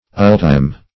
ultime - definition of ultime - synonyms, pronunciation, spelling from Free Dictionary Search Result for " ultime" : The Collaborative International Dictionary of English v.0.48: Ultime \Ul"time\ ([u^]l"t[i^]m), a. Ultimate; final.